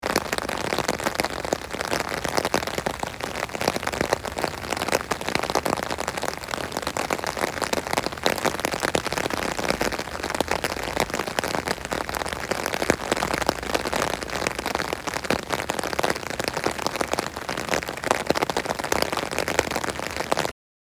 Download Free Foley Sound Effects | Gfx Sounds
Tropical-storm-rain-on-umbrella-medium-intensity.mp3